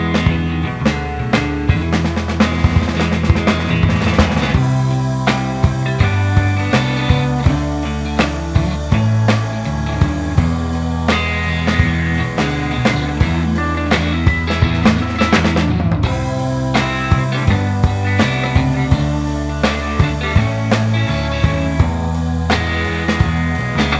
no Backing Vocals Blues 3:34 Buy £1.50